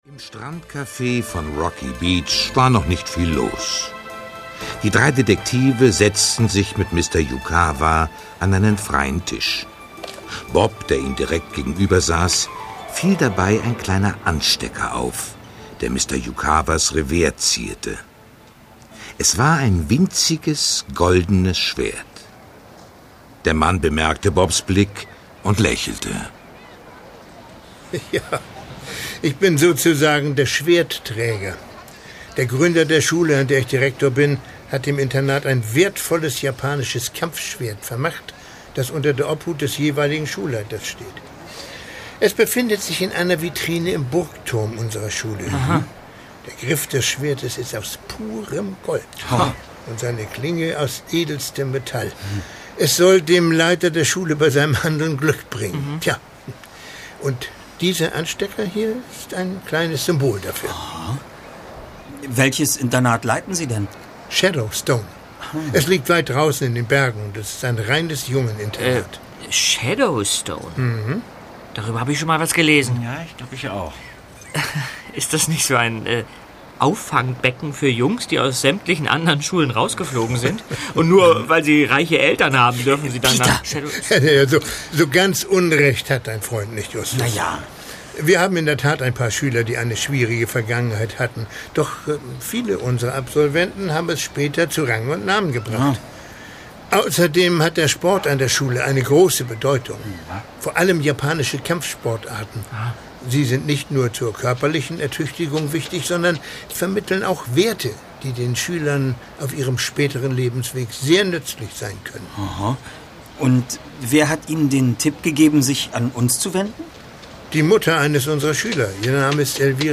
Die drei ??? - Die Rache der Samurai | Physical CD Audio drama
rzähler - Thomas Fritsch